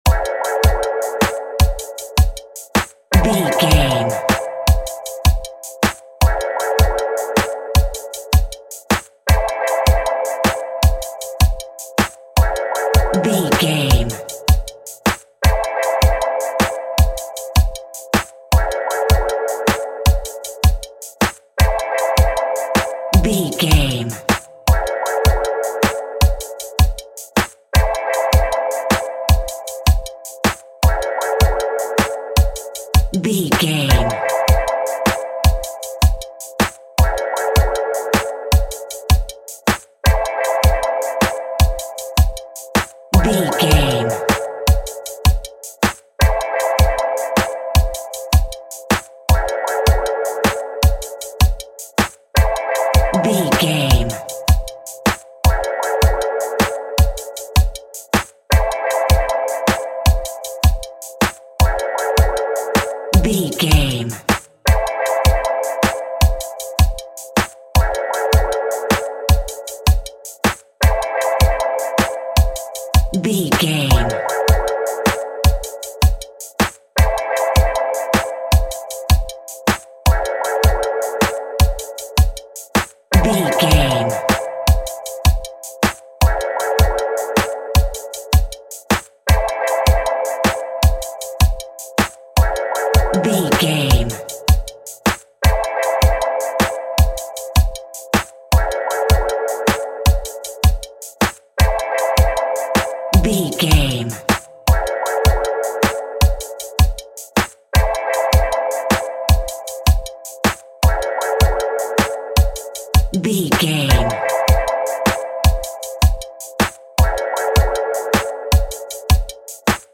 Hip Hop Music from London.
Aeolian/Minor
Slow
Drum and bass
sub bass
synth drums
synth leads
synth bass